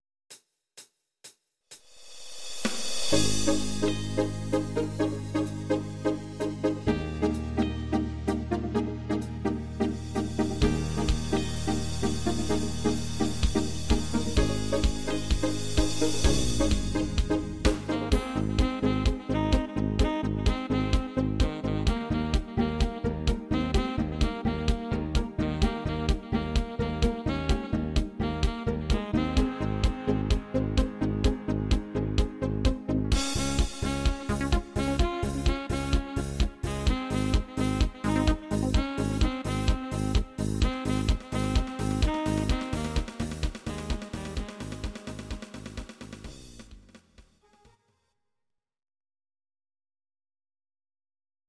Audio Recordings based on Midi-files
Our Suggestions, Pop, German, 2010s